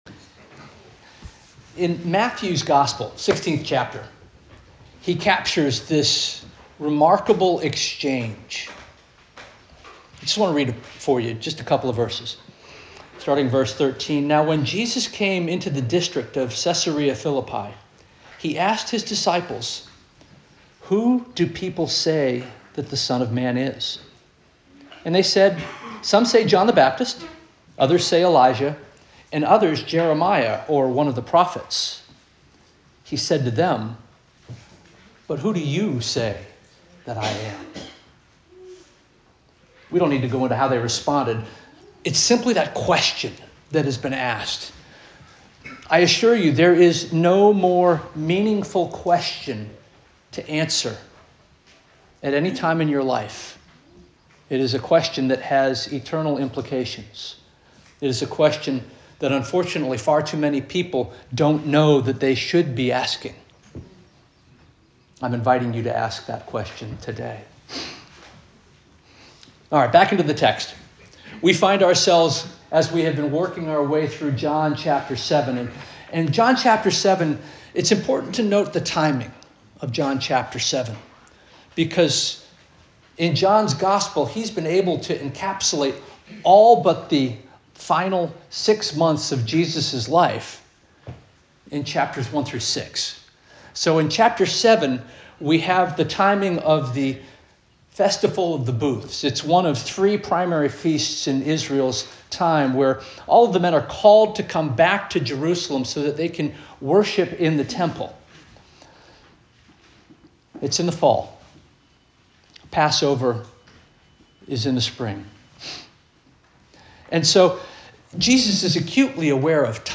March 1 2026 Sermon - First Union African Baptist Church